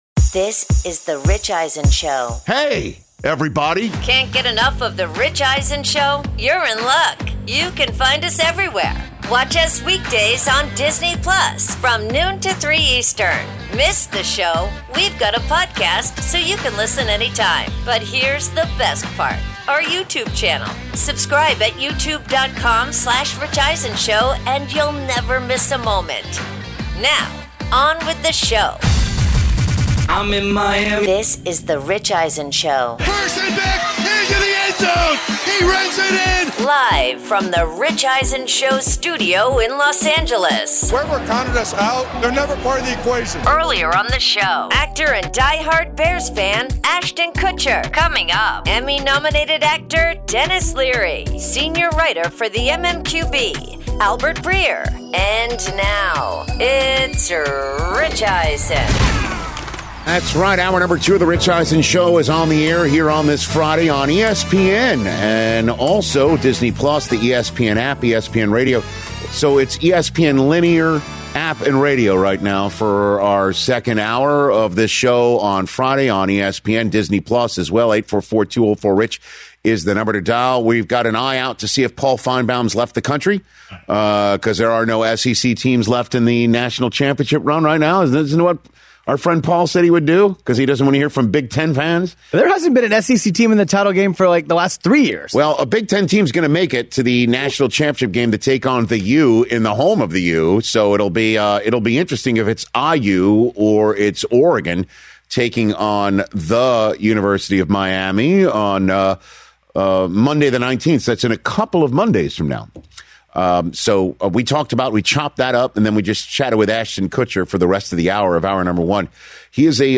Hour 2: ‘What’s More Likely,’ plus Actor/Comedian Denis Leary In-Studio